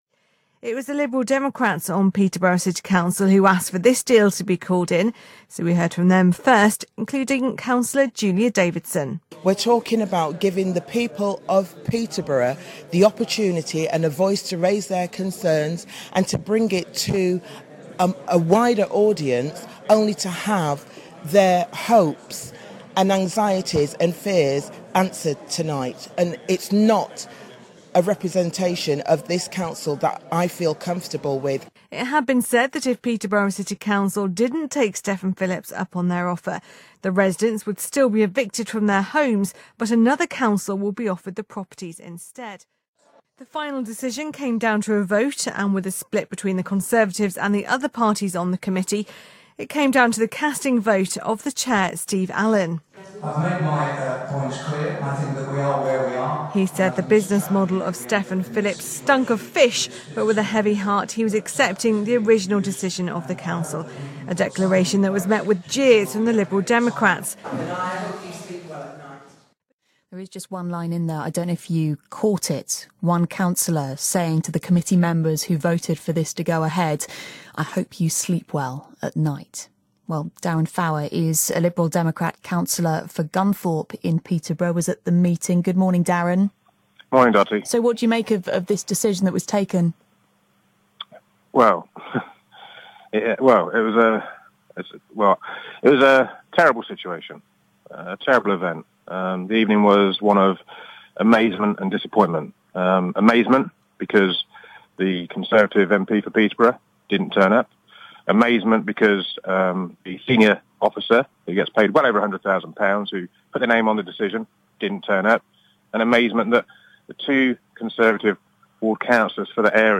Interview on BBC Cambs about St Michael's Gate
Following the Call-In meeting at the Town Hall, regarding the Tory deal to move homeless people into a Peterborough estate where the tenants have been evicted, I was interviewed on the local BBC Breakfast show.